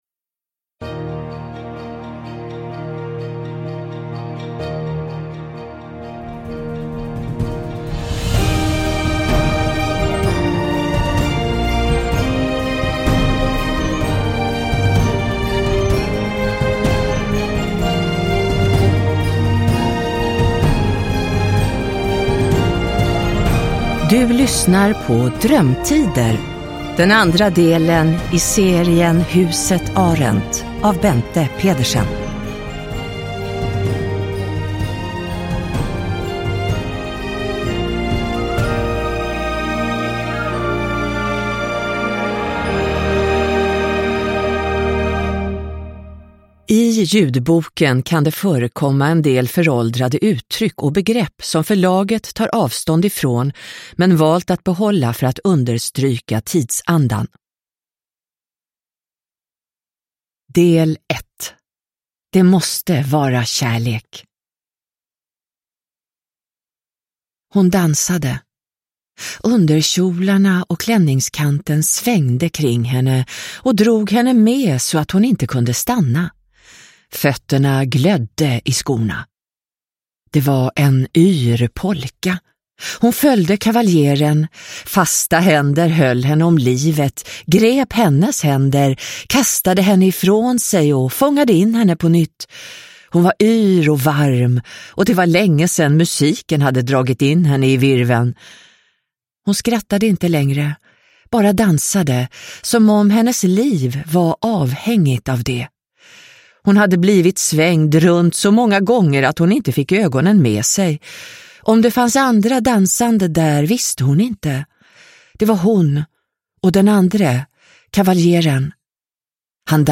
Drömtider – Ljudbok – Laddas ner